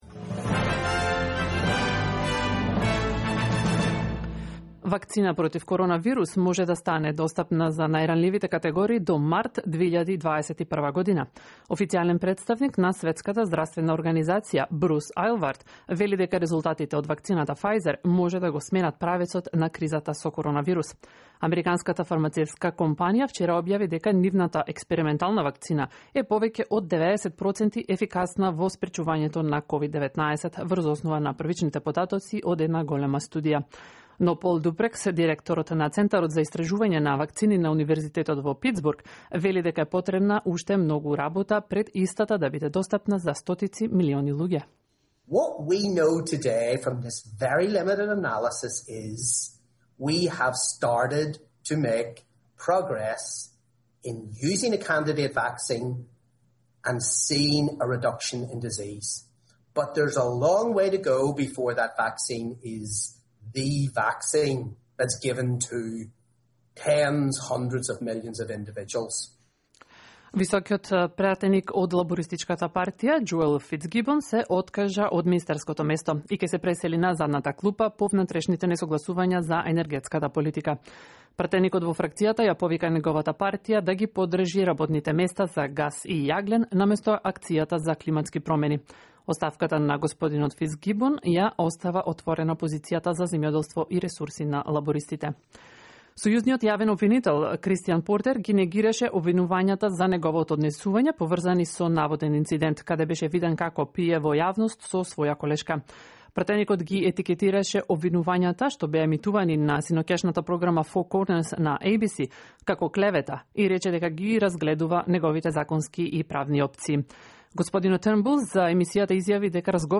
Вести на СБС на македонски јазик 10 ноември 2020